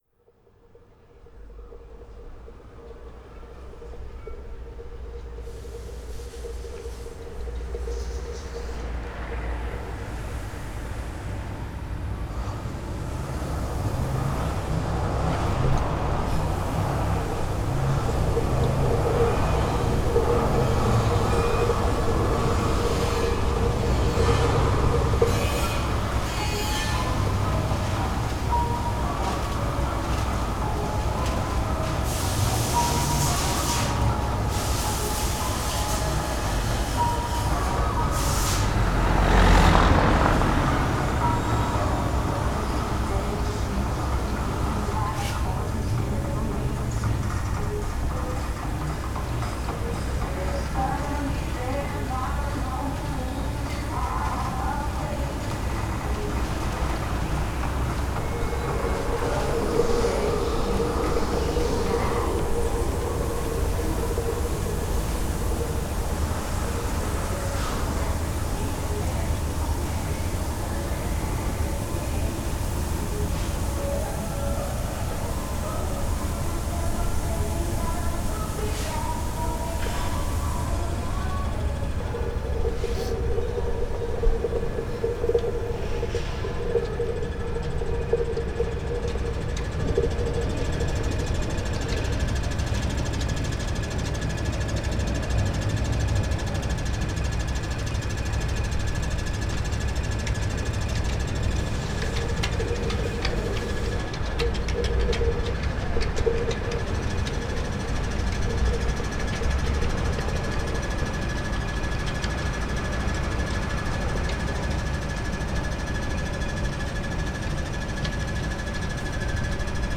walking past car painting workshops
ambience, work sounds.
Adres: Polluxstraat, Binckhorst, Laak, The Hague, South Holland, Netherlands, 2516 AX, Netherlands